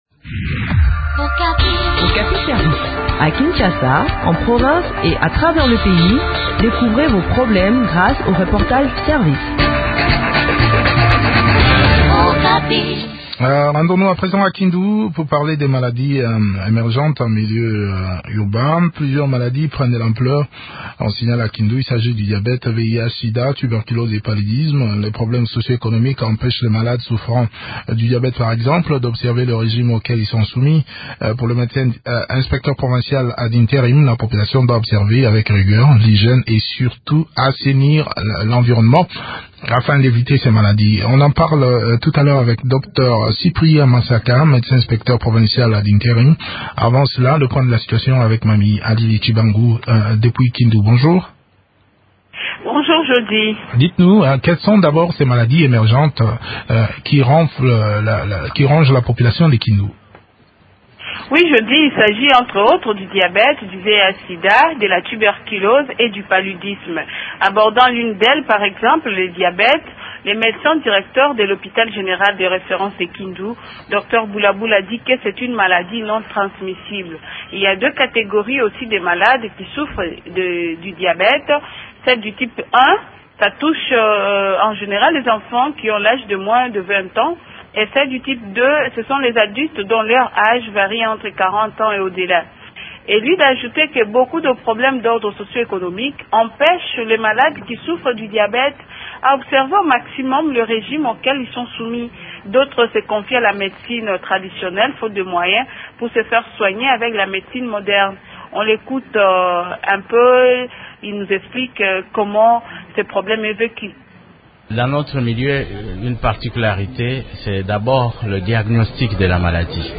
inspecteur provincial de la Santé ad intérim.